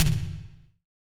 Index of /90_sSampleCDs/Best Service - Real Mega Drums VOL-1/Partition G/SDS V TOM ST